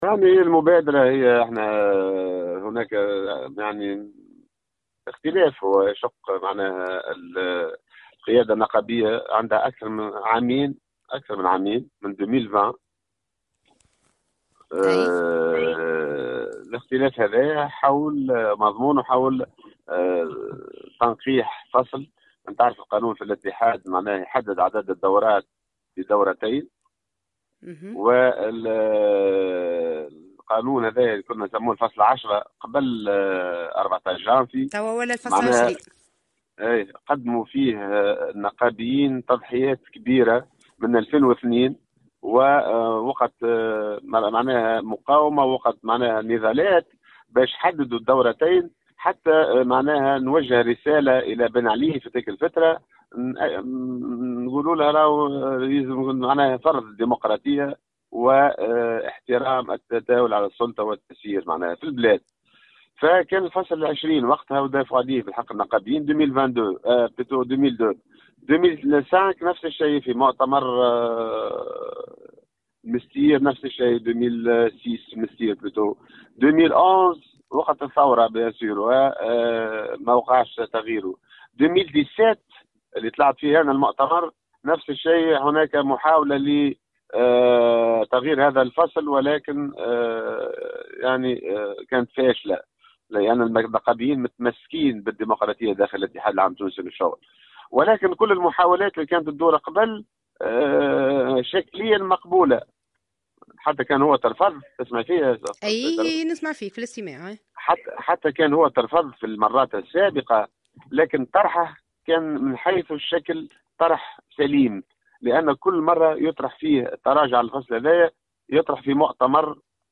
واوضح في تصريح للجوهرة أف أم، ان هناك اختلافا يشق القيادة النقابية منذ 2020 حول تنقيح الفصل 20 الذي يحدد عدد الدورات والمسؤولية القيادية صلب المكتب التنفيذي للاتحاد بدورتين لاغير والتي تم تنقيحها بمقتضى مؤتمر استثنائي غير انتخابي في سوسة .